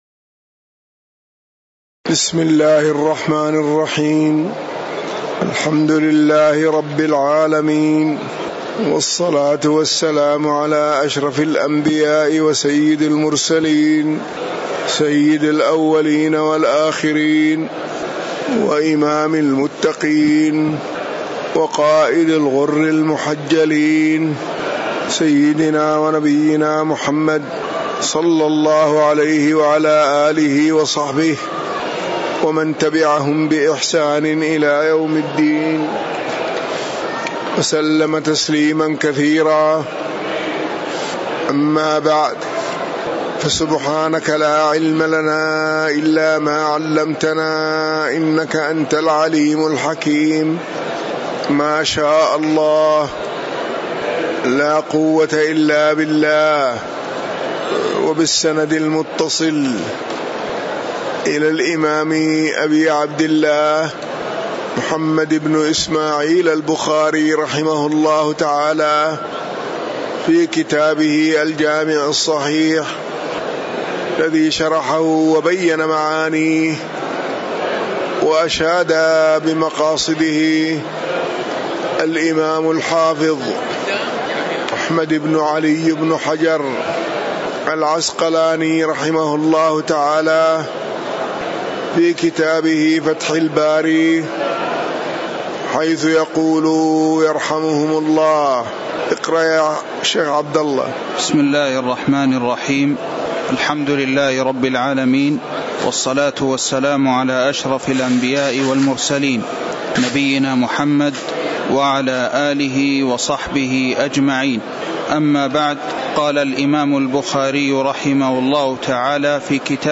تاريخ النشر ٨ صفر ١٤٤١ هـ المكان: المسجد النبوي الشيخ